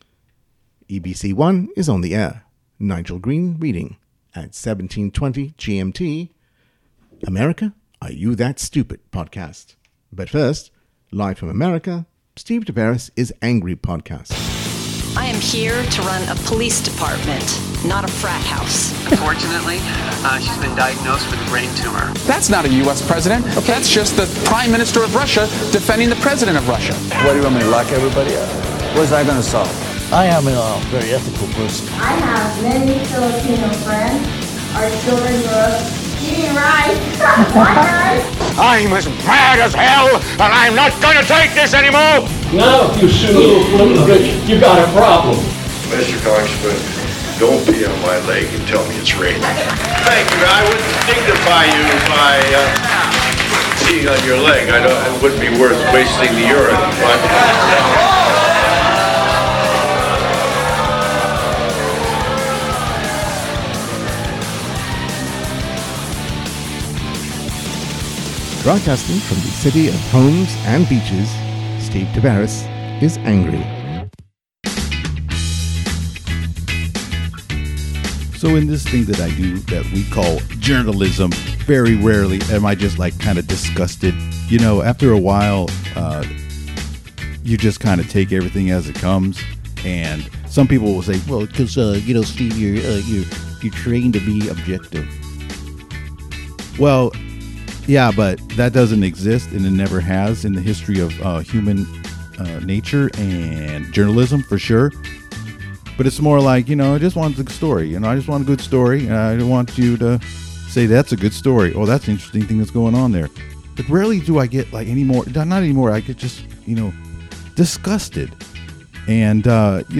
Interview with Hayward City Councilmember Sara Lamnin
In the Hot Seat, I chat with Hayward Councilmember Sara Lamnin, who is running for re-election in what will likely be a tough campaign among three strong candidates for two at-large seats on the council.